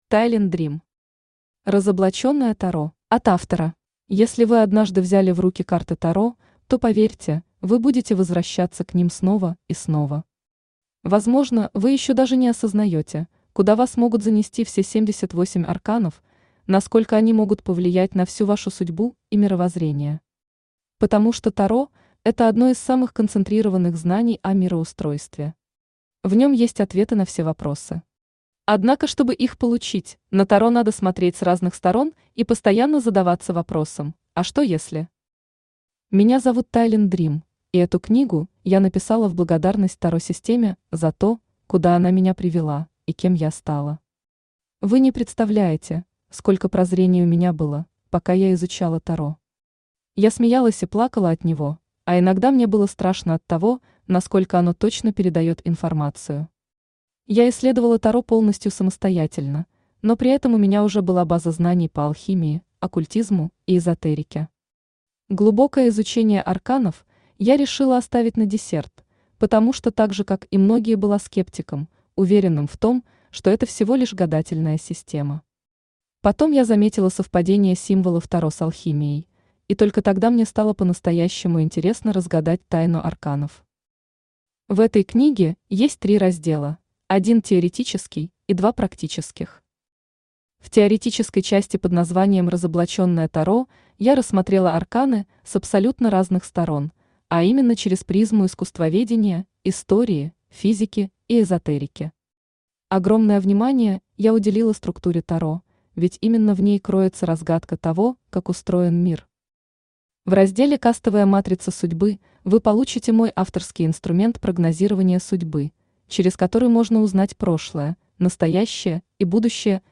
Аудиокнига Разоблаченное Таро | Библиотека аудиокниг
Aудиокнига Разоблаченное Таро Автор Тайлин Дрим Читает аудиокнигу Авточтец ЛитРес.